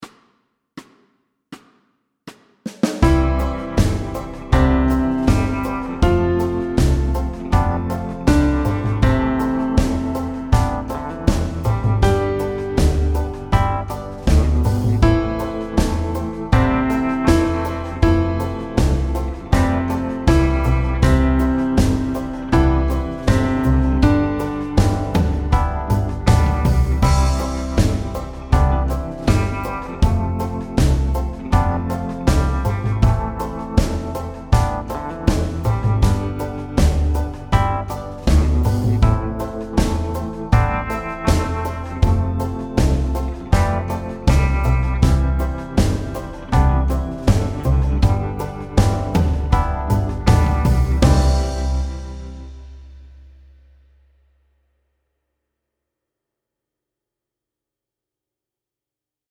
Fast C instr (demo)